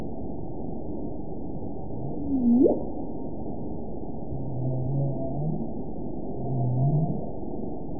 target species NRW